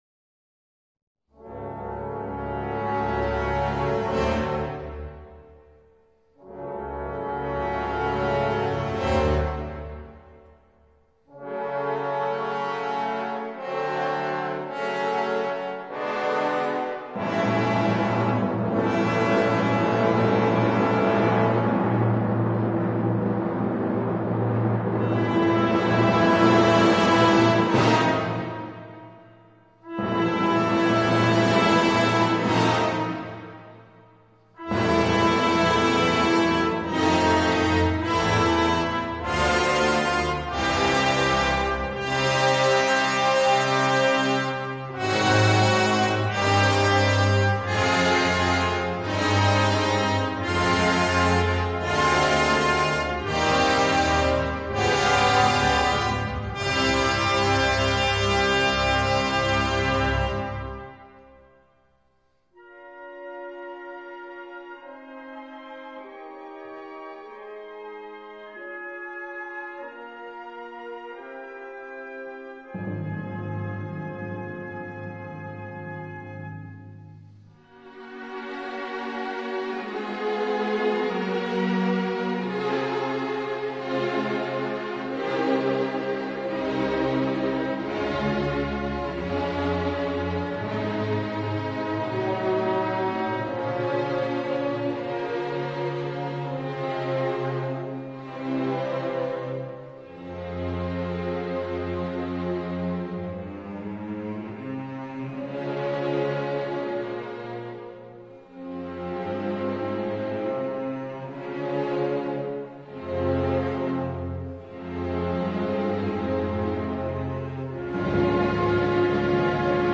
音樂類型：古典音樂
從銅管怒號的激動情緒下唱起來。這段起奏，莊嚴、壓抑而苦悶，彷彿暗示在俄國暴政下的芬蘭人民內心澎湃的吶喊，令人聞之動容。
》主題由木管吹奏，帶動絃樂唱和，也有少數版本以合唱來歌詠這段神聖而抒情的祈禱。
接著又由鼓號聲帶動革命的熱情，將整闋樂曲推向最激烈高潮的結尾。